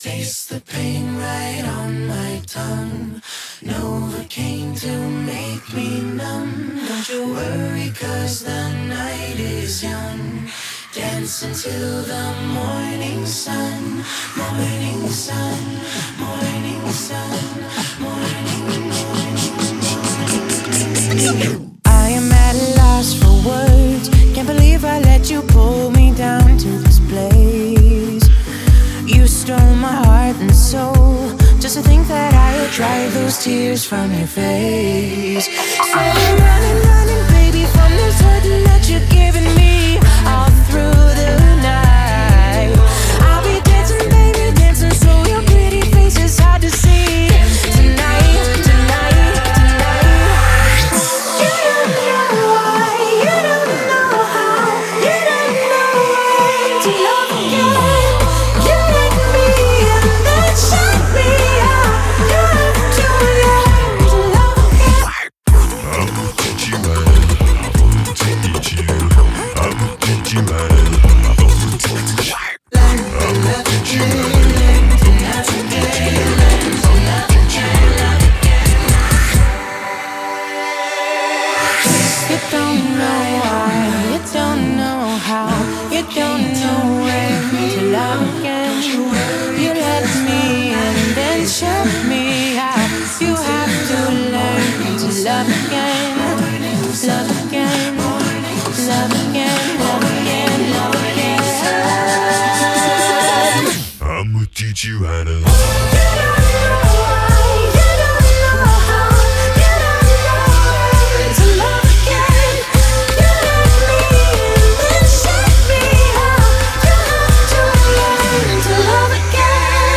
BPM66-132
Audio QualityMusic Cut